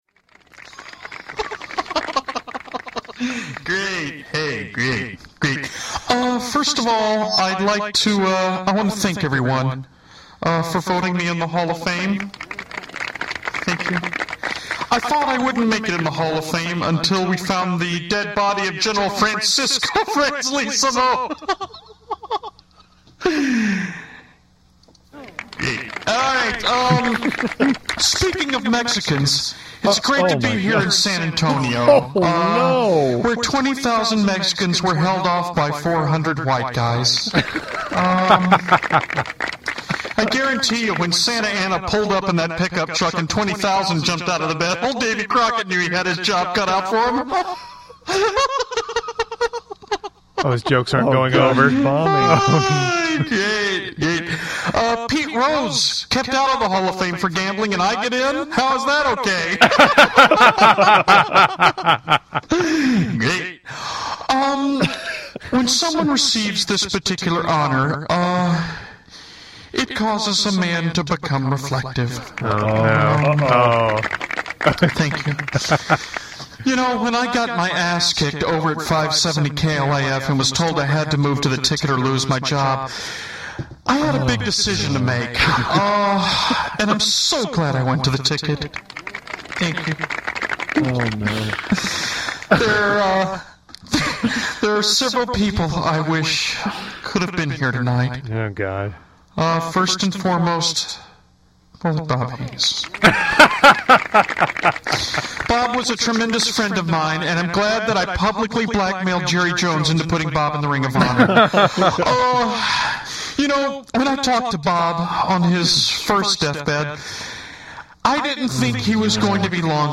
Especially classic audio from back in the day.